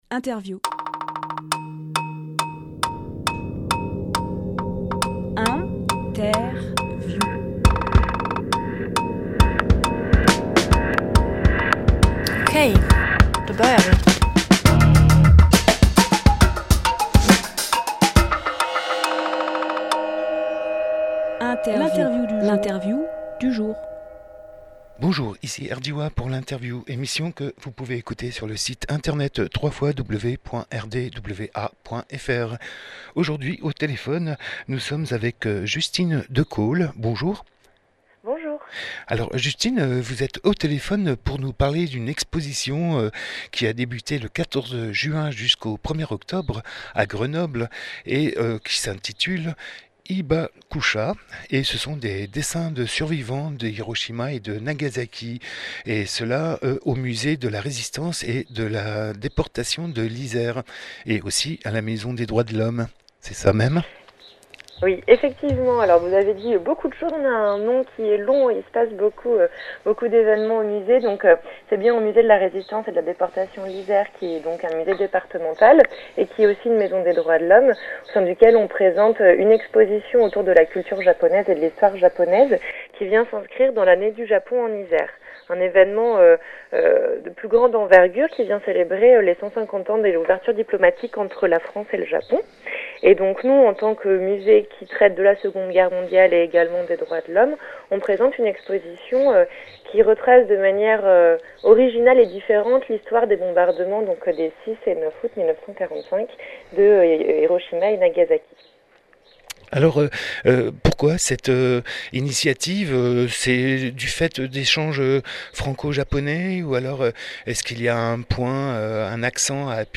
Emission - Interview Hibakusha, dessins de survivants d’Hiroshima et Nagasaki Publié le 24 juillet 2018 Partager sur…
Lieu : Studio RDWA